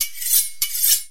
Звуки заточки ножа
На этой странице представлены звуки заточки ножа в разных вариациях: от резких металлических скрежетов до монотонных ритмичных движений.